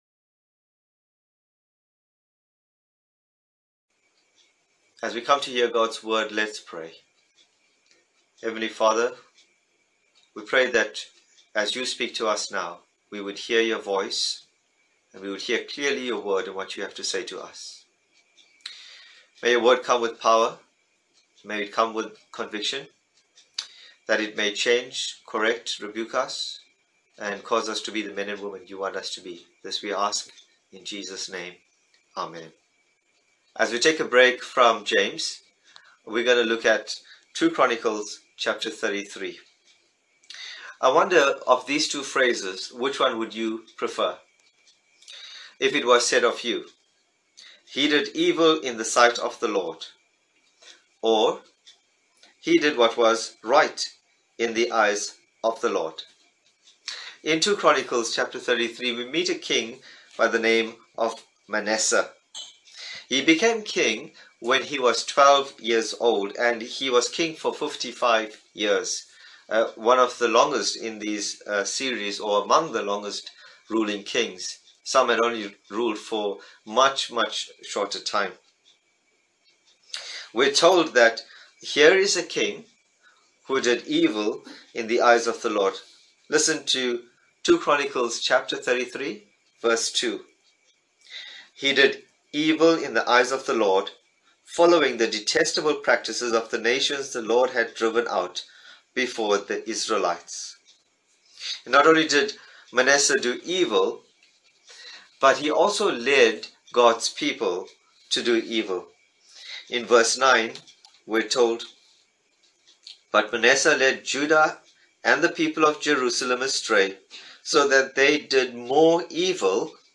Bible Text: 2 Chronicles 33 | Preacher